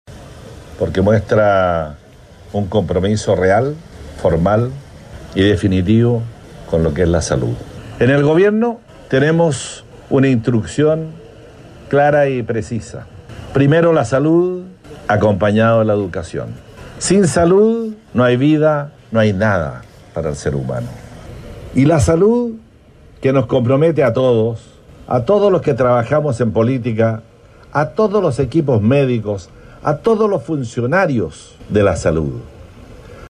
El Intendente de Los Lagos, Harry Jürgensen, destacó el compromiso del Gobierno con acercar la salud a la comunidad.
La entrega de las máquinas tuvo lugar en la plaza de Castro, donde se dieron cita autoridades regionales, provinciales y comunales, además de profesionales del ámbito de la salud.